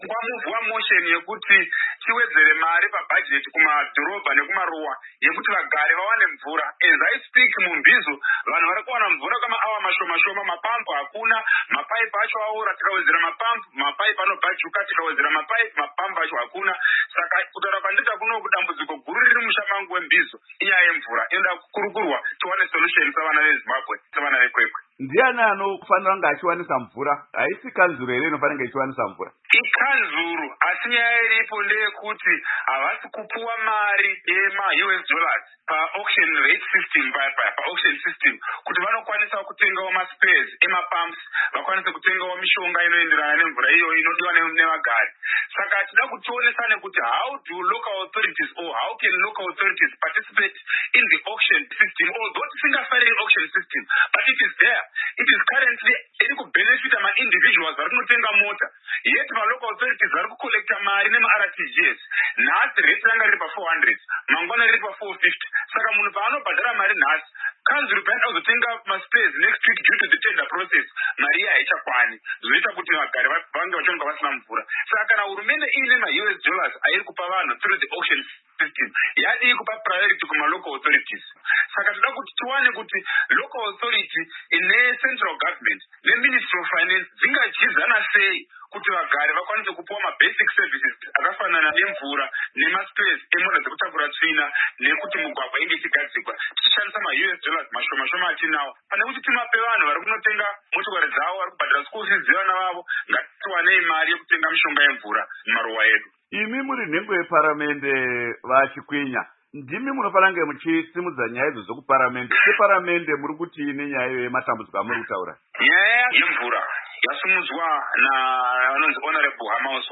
Hurukuro naVaSettlement Chikwinya